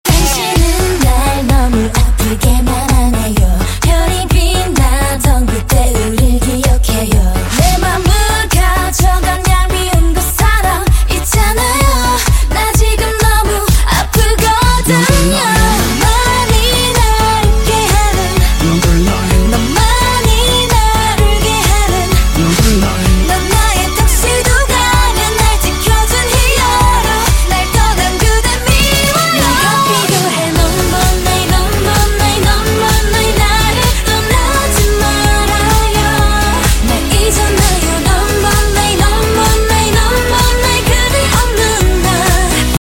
Thể loại nhạc chuông: Nhạc hàn quốc